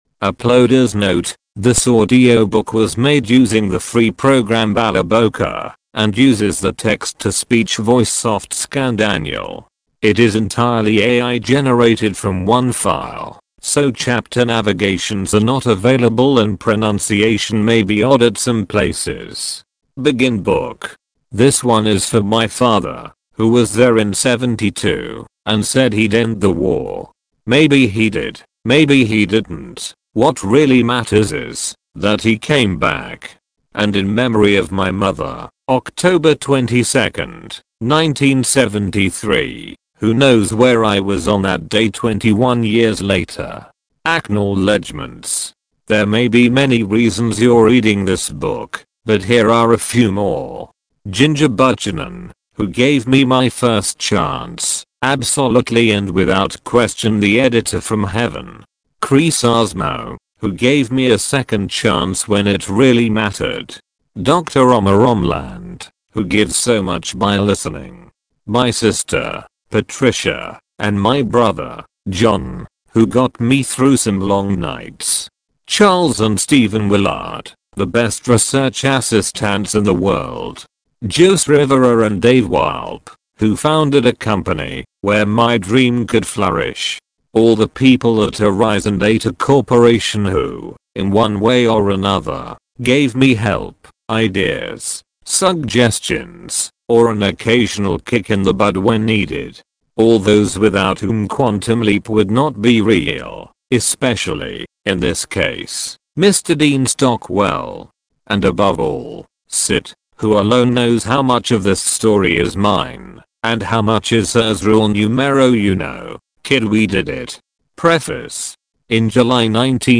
Download the MP3 audiobook: Download Listen to the audiobook: Read the novel online :